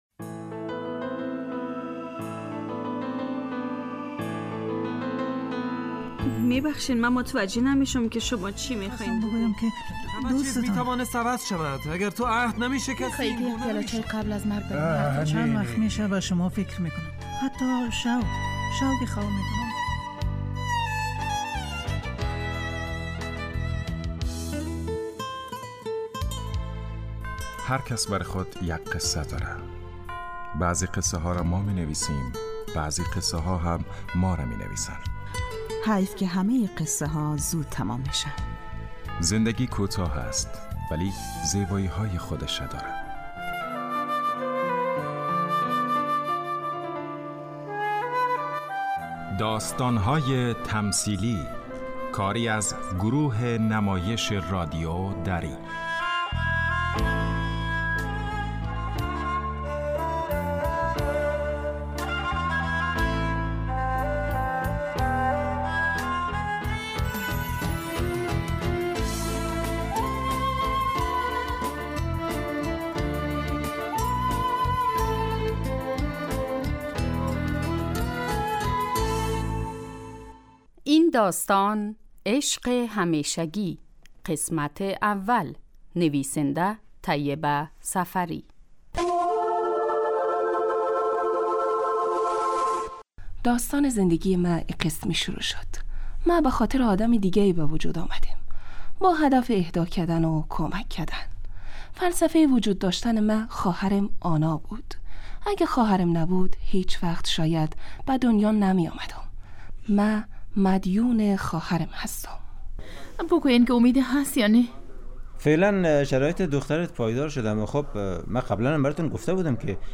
داستان تمثیلی / عشق همیشگی